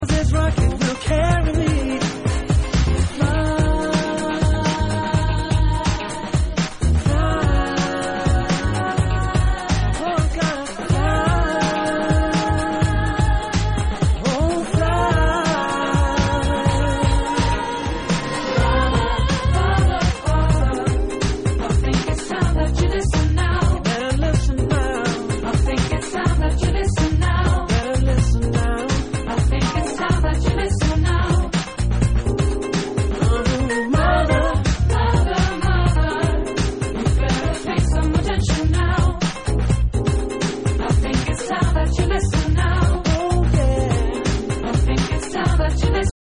Jazz house